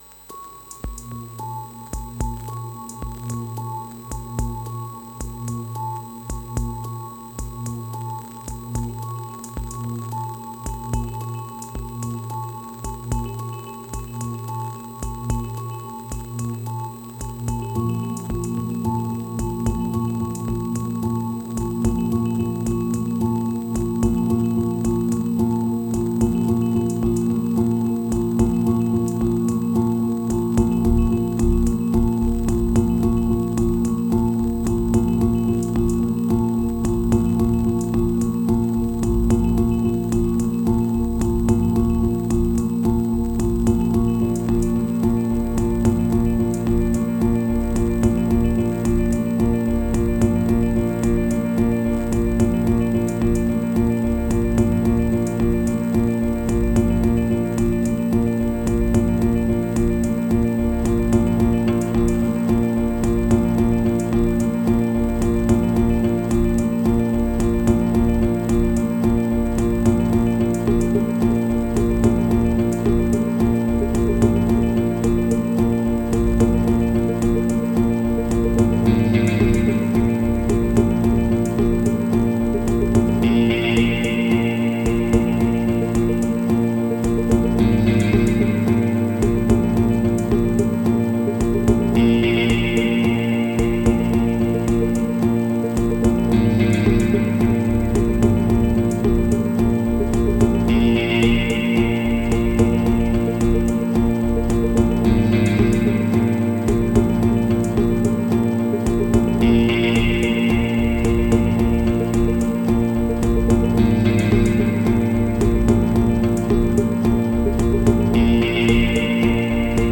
Simple sample guitare rythmique.